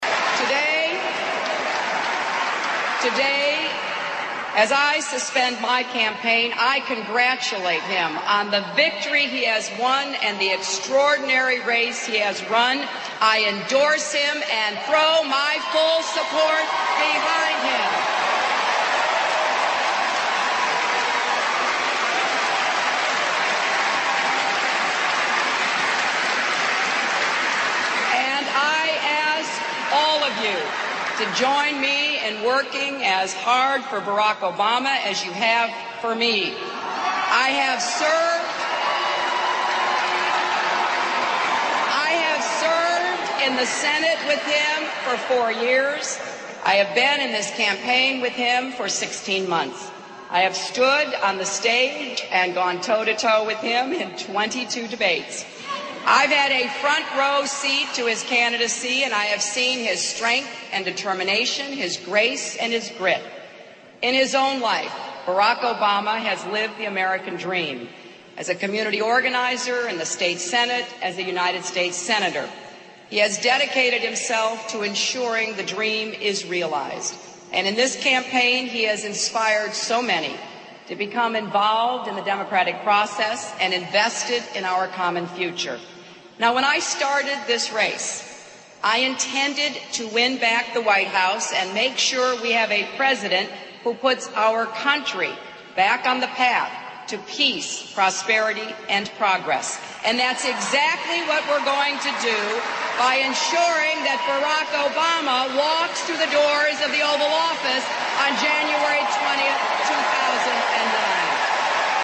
名人励志英语演讲 第71期:我放弃了 但我会继续战斗(5) 听力文件下载—在线英语听力室